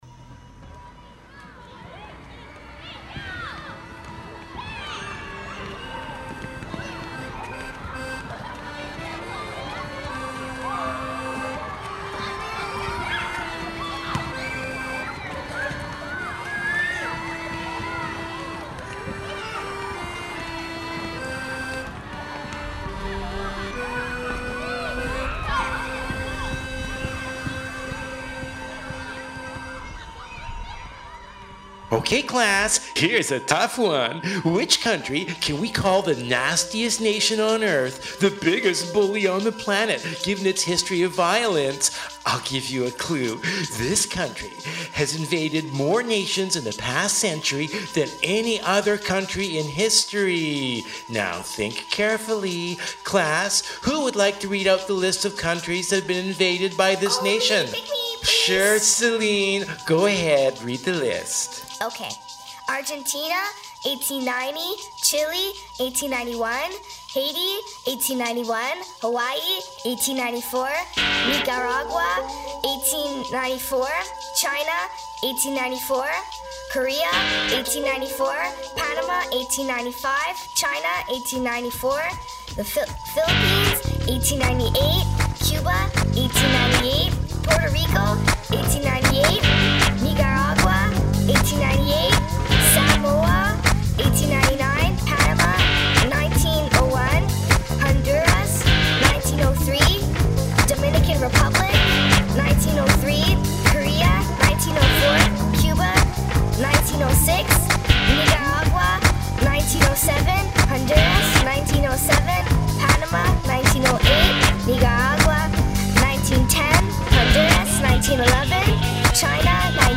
Music